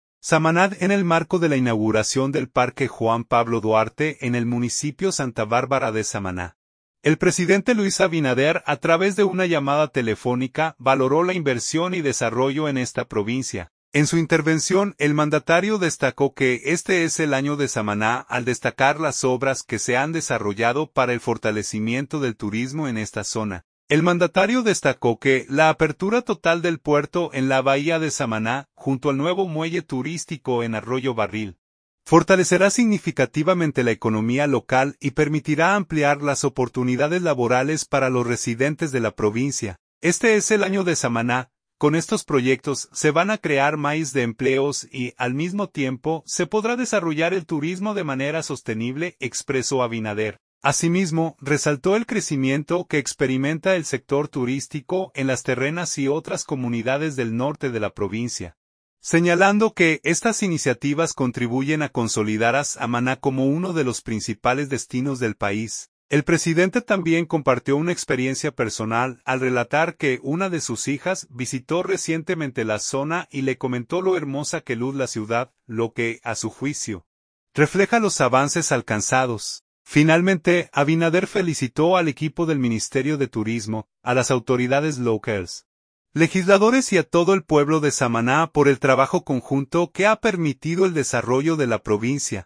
Samaná.- En el marco de la inauguración del Parque Juan Pablo Duarte en el municipio Santa Bárbara de Samaná, el presidente Luis Abinader a través de una llamada telefónica valoró la inversión y desarrollo en esta provincia.